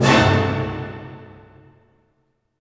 Index of /90_sSampleCDs/Roland LCDP08 Symphony Orchestra/HIT_Dynamic Orch/HIT_Orch Hit Dim
HIT ORCHD01R.wav